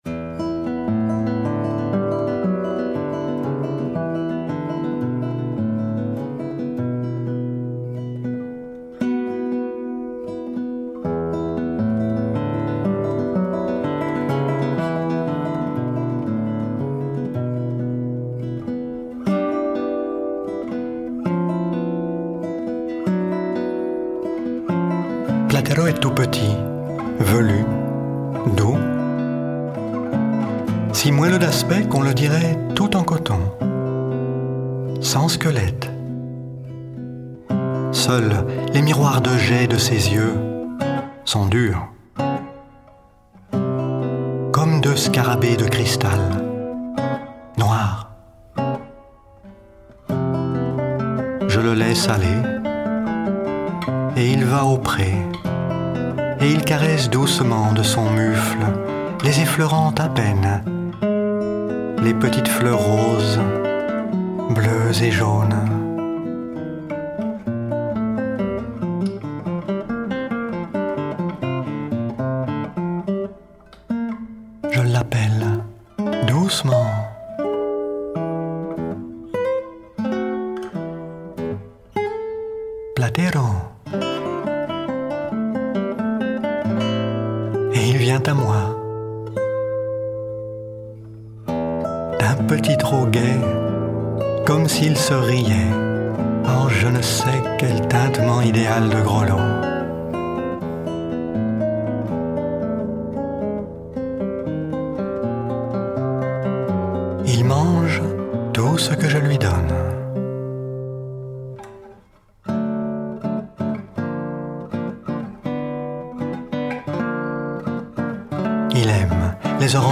pour narrateur et guitare